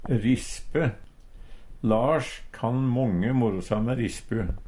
rispe kort forteljing, må vera noko å høyre på Eintal ubunde Eintal bunde Fleirtal ubunde Fleirtal bunde ei rispe rispa rispu rispun Eksempel på bruk Lars kan månge morosame rispu. Tilleggsopplysningar Høyr på uttala Ordklasse: Substantiv hokjønn Attende til søk